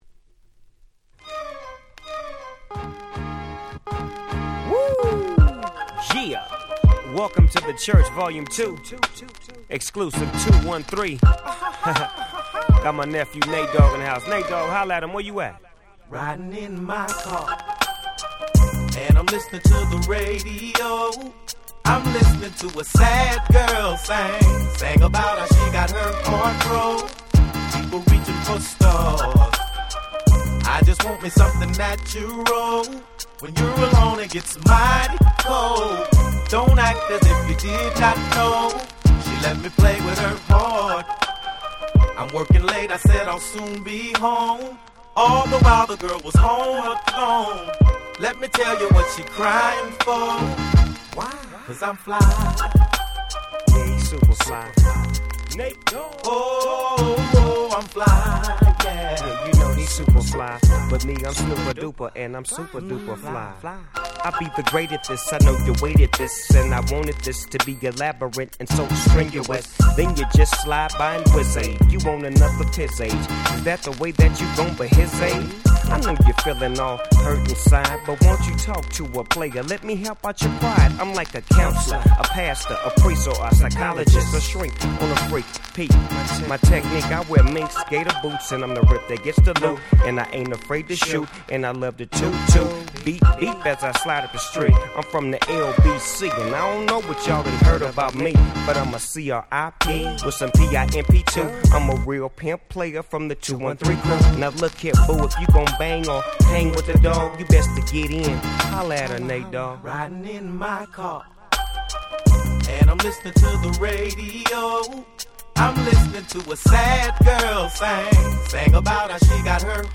04' Super Nice West Coast Hip Hop / R&B !!